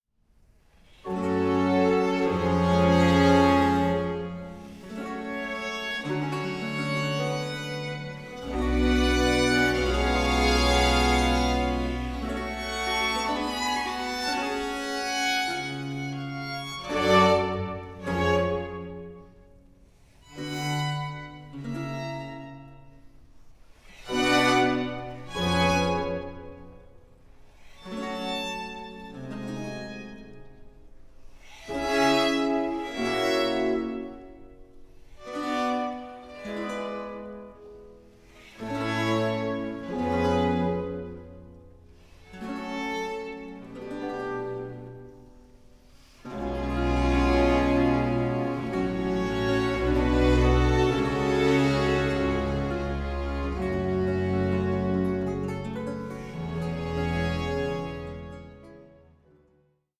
Allegro 2:05